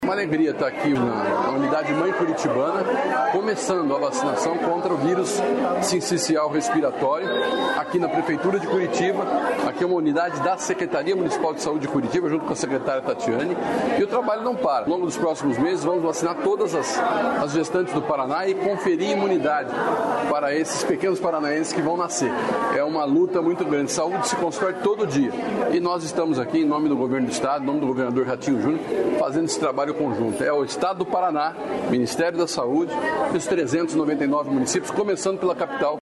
Sonora do secretário de Estado da Saúde, Beto Preto, sobre vacinação contra Vírus Sincicial Respiratório